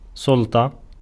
ملف تاريخ الملف وصلات معلومات الصورة (ميتا) Ar-سلطة.ogg  (Ogg Vorbis ملف صوت، الطول 1٫0ث، 119كيلوبيت لكل ثانية) وصف قصير ⧼wm-license-information-description⧽ Ar-سلطة.ogg English: Pronunciation of word "سلطة" in Arabic language. Male voice. Speaker from Tiznit, Morocco.